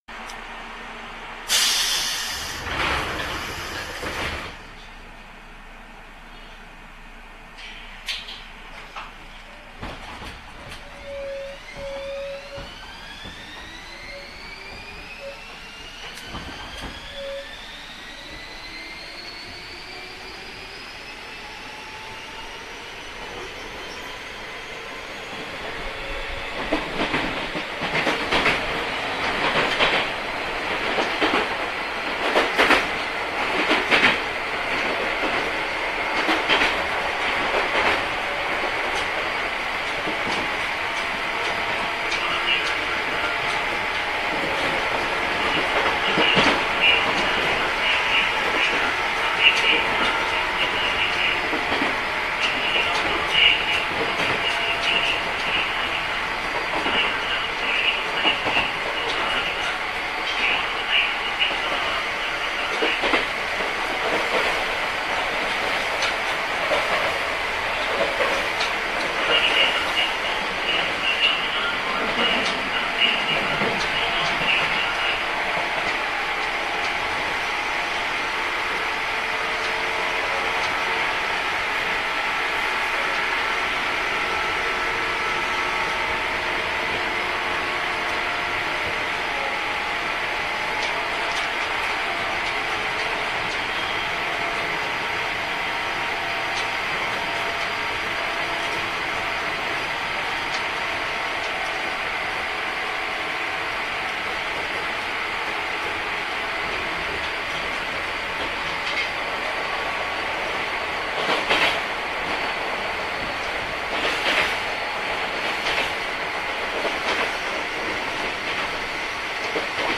そして何ともいえない豪快な音がするのはこちら。
小田急線　秦野〜渋沢（9103）